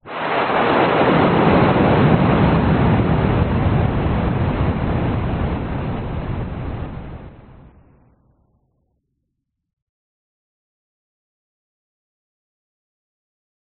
woosh fx 1
描述：在F.I.舞曲中，有一种爆炸的感觉，是一种额外的触摸。